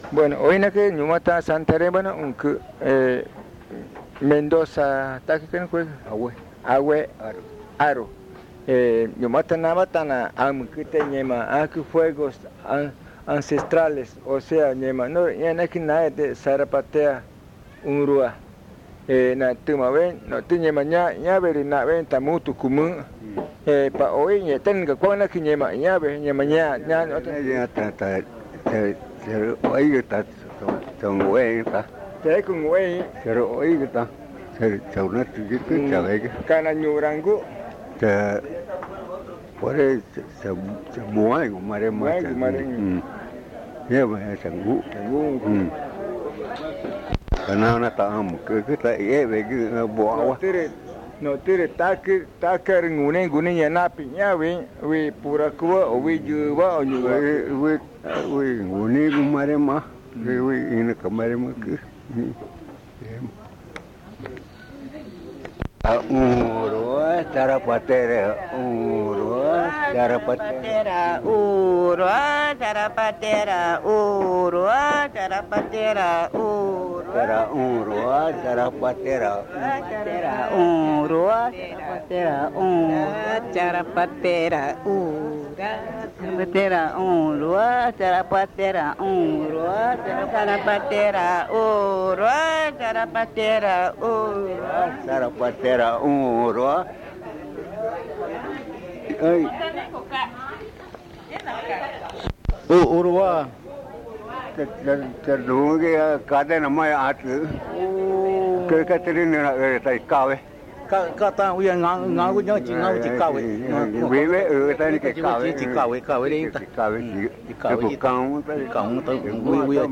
San Juan del Socó, río Loretoyacu, Amazonas (Colombia)